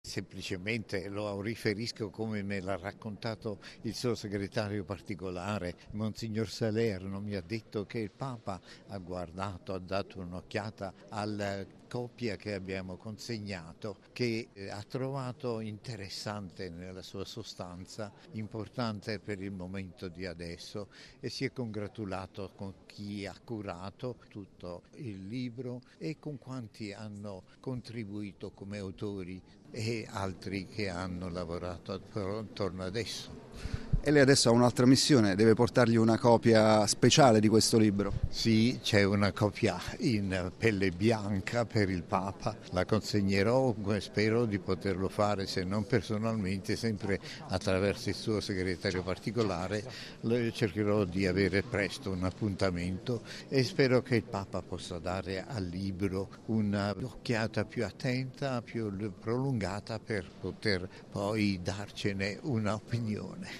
Ascolta l’intervista al cardinale Francesco Monterisi